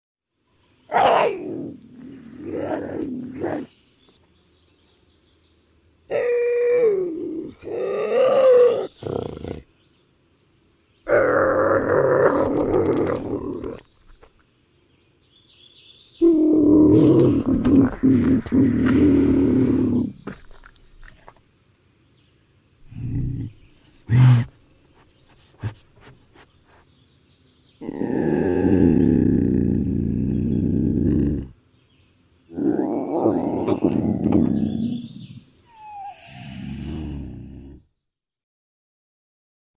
Bobcat
Expressive growls made by an individual bobcat-
The vocalizations made by Bobcats include meows, growls, snarls, hisses, yowls, caterwauls, squalls and screams.